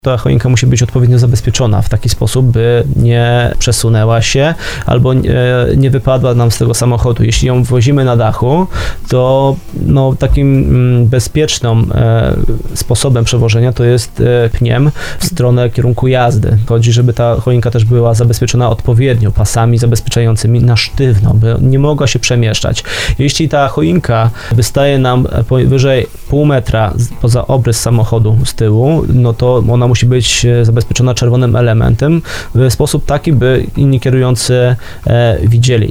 tłumaczył na antenie Radia RDN Małopolska